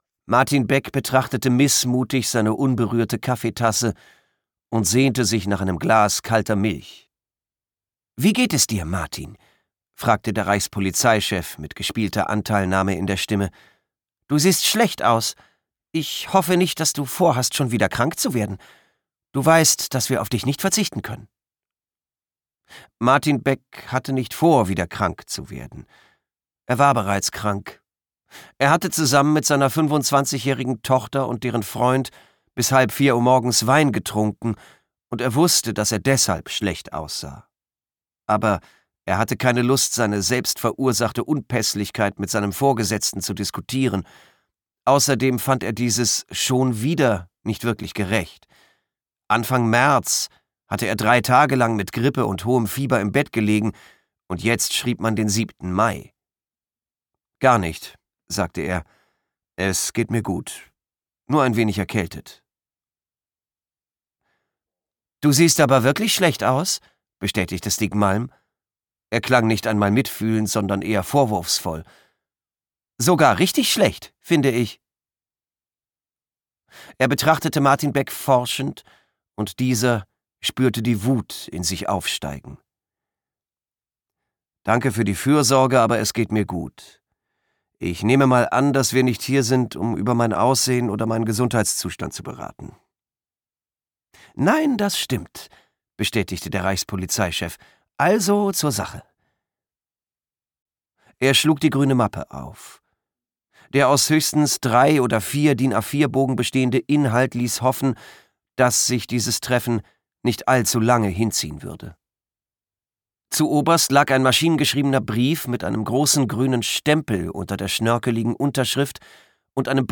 Schweden-Krimi
Gekürzt Autorisierte, d.h. von Autor:innen und / oder Verlagen freigegebene, bearbeitete Fassung.